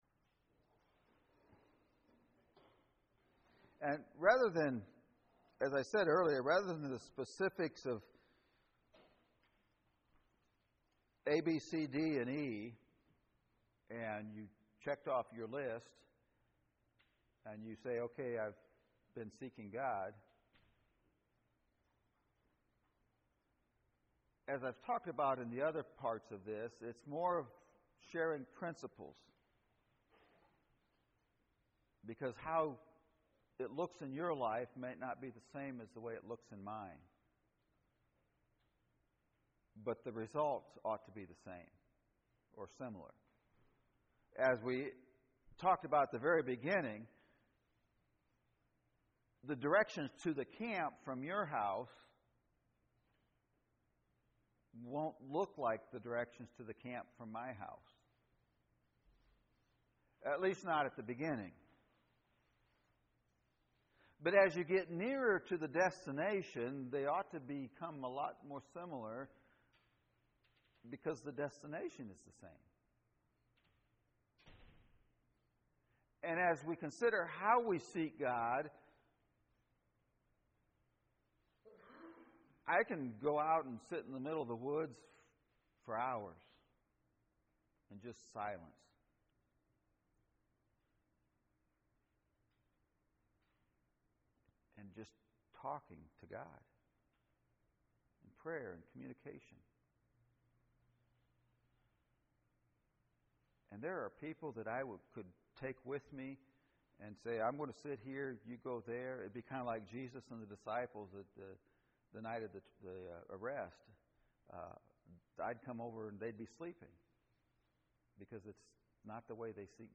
This is the audio of session three from the May 4, 2019 Seeking God Prayer Event held at Michiana Christian Camp.